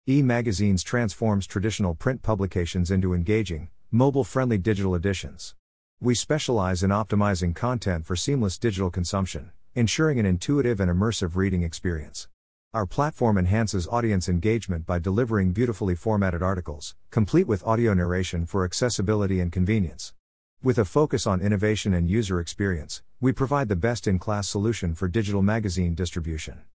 We turn text into lifelike speech by leveraging Neural Text-to-Speech systems to create natural-sounding human audio that keeps your subscribers engaged.